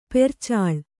♪ percāḷ